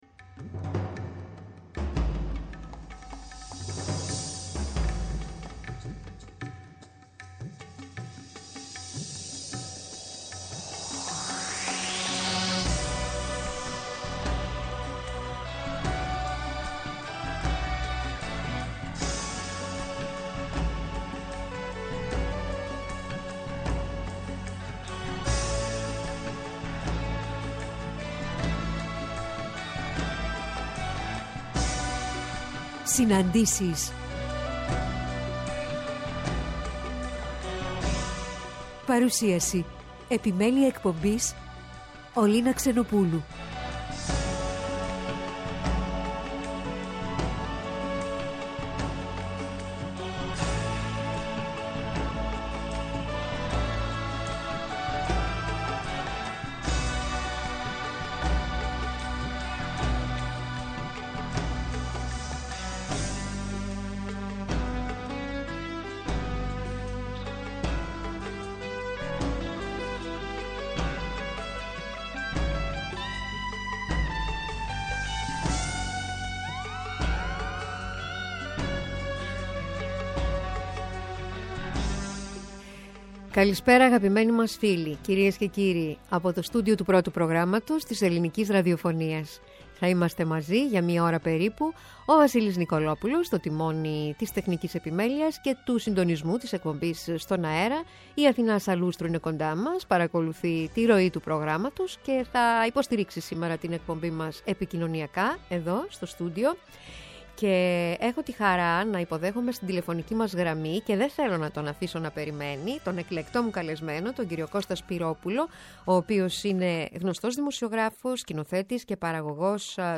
Στις ΣΥΝΑΝΤΗΣΕΙΣ σήμερα 16:00-17:00 στο Πρώτο Πρόγραμμα καλεσμένοΙ :